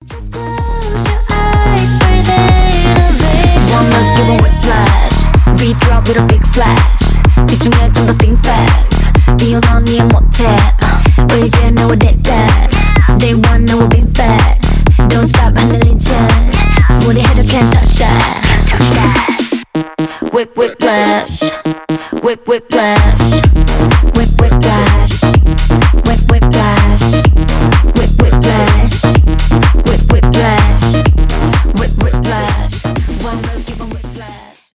поп , k-pop , pop rap
trap
танцевальные
tech house